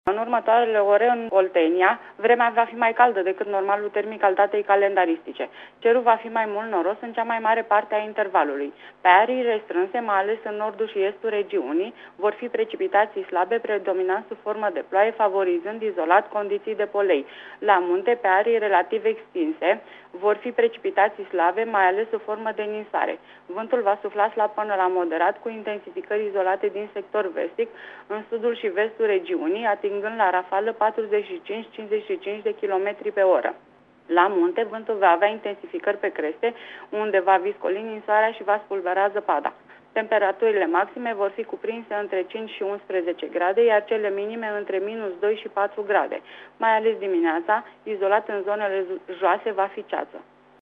Prognoza meteo 28 decembrie (audio)